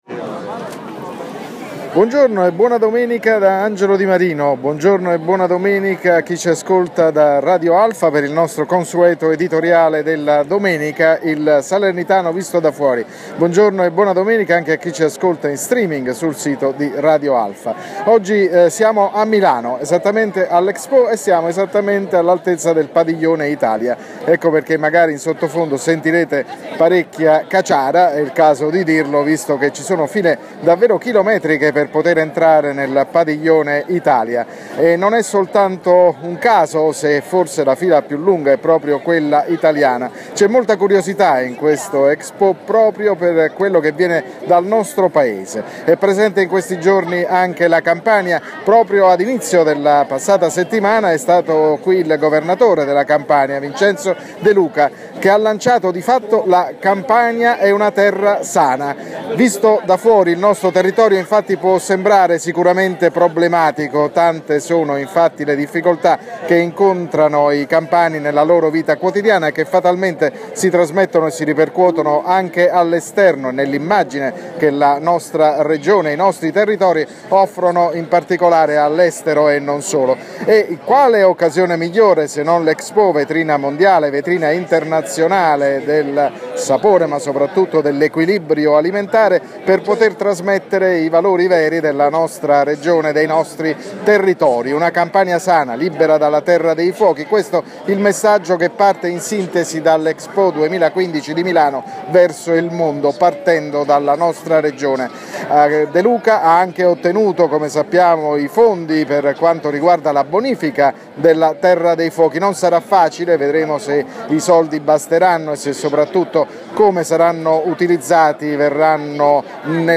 L’editoriale della domenica andato in onda sulle frequenze di Radio Alfa questa mattina.